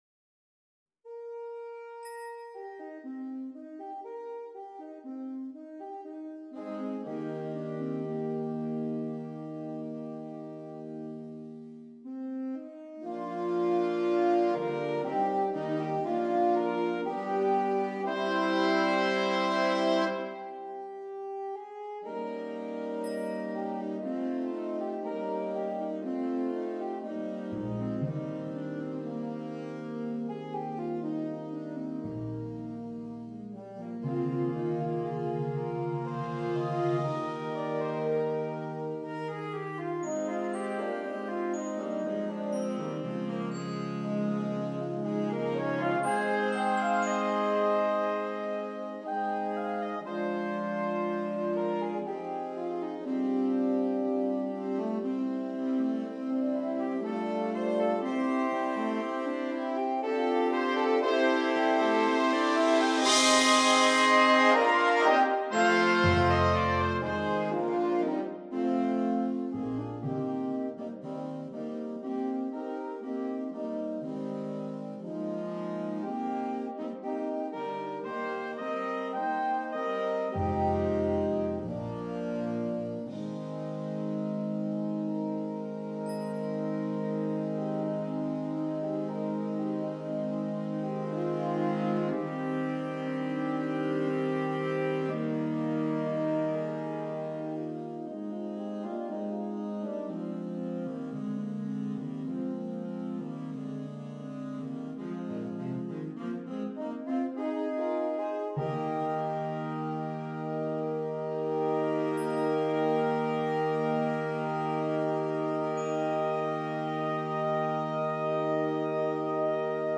Saxophone Ensemble